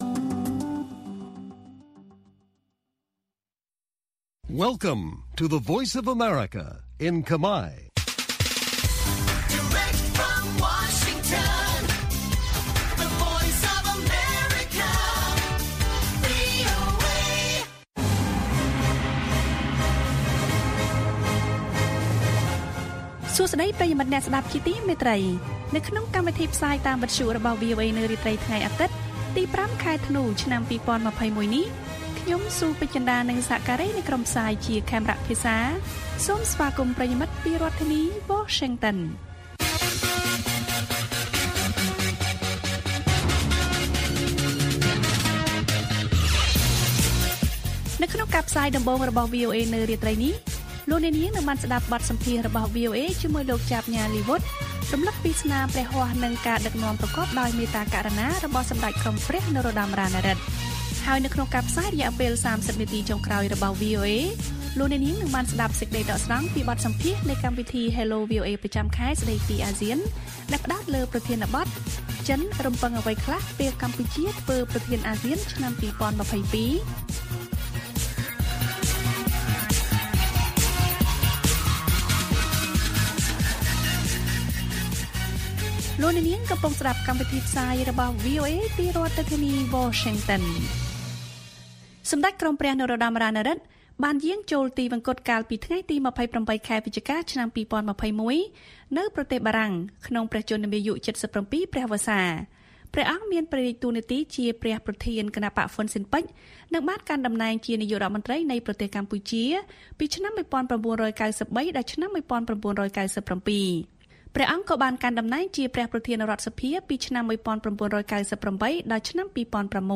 បទសម្ភាសន៍ដកស្រង់ពីកម្មវិធី Hello VOA៖ ចិនរំពឹងអ្វីខ្លះពេលកម្ពុជាធ្វើជាប្រធានអាស៊ាននៅឆ្នាំ២០២២?